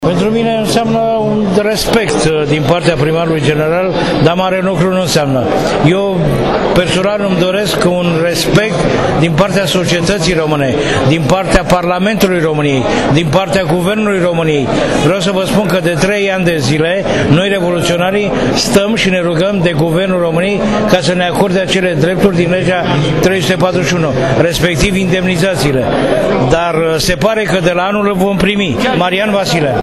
diplomaPrimăria Capitalei a acordat diplome revoluționarilor din 1989, în cadrul unei festivități.
Ca de obicei, acordarea premiilor a stârnit contestări vehemente din partea unora dintre revoluționari la adresa celor premiați.